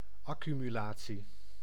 Ääntäminen
Ääntäminen France: IPA: /a.ky.my.la.sjɔ̃/ Haettu sana löytyi näillä lähdekielillä: ranska Käännös Ääninäyte Substantiivit 1. accumulatie {f} 2. opeenhoping {f} 3. samenscholing 4. opeenstapeling Suku: f .